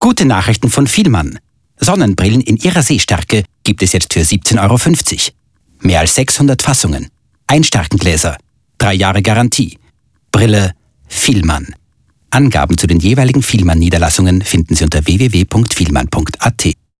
wienerisch
Sprechprobe: Werbung (Muttersprache):